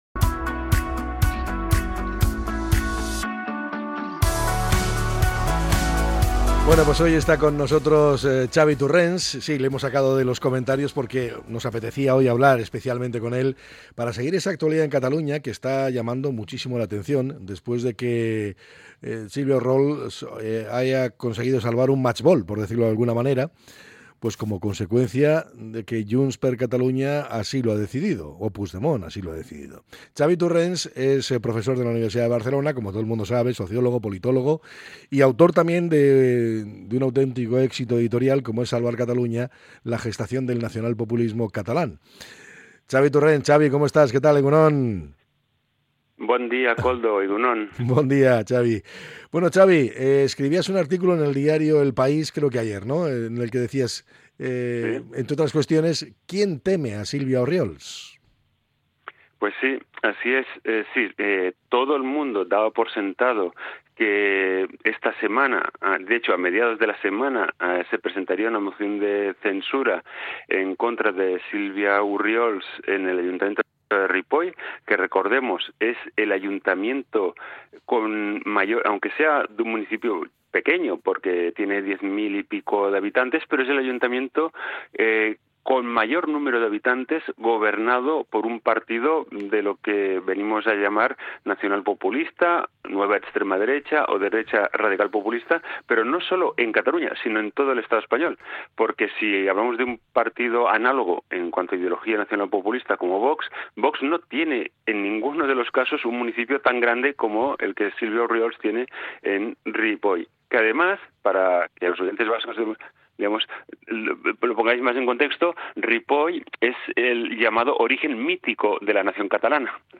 El sociólogo y politólogo